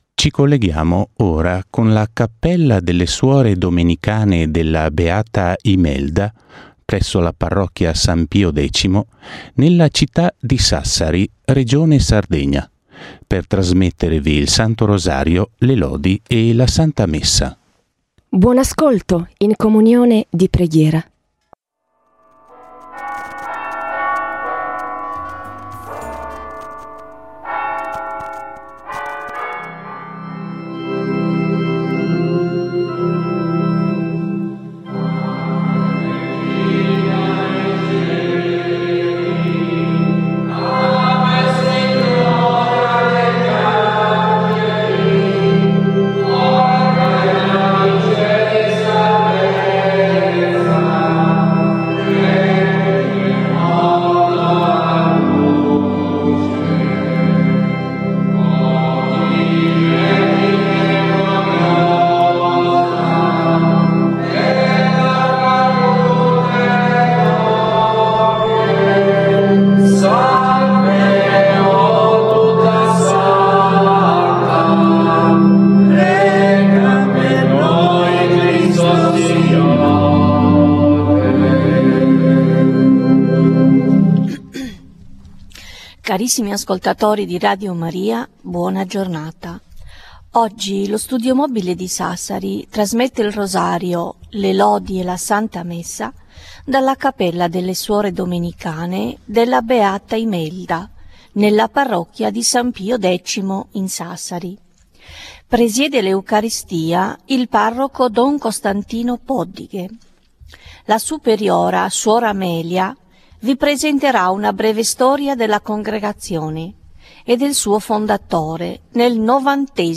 E' disponibile nella sezione La Voce del sito la registrazione della diretta con Radio Maria del 16 gennaio dalla Cappella delle Suore.